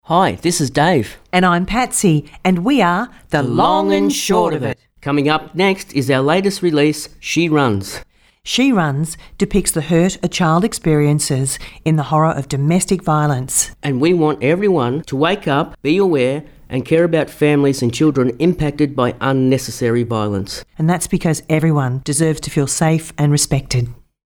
dd709-the-long-and-short-of-it-radio-id-she-runs-24secs